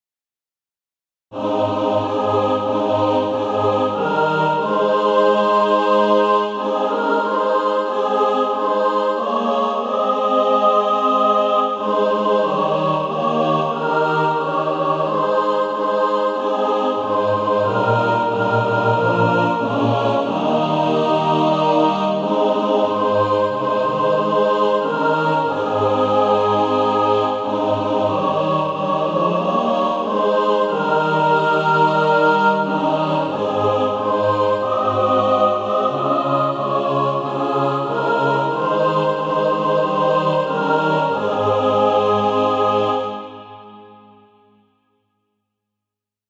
And also a mixed track to practice to **
Practice then with the Chord quietly in the background.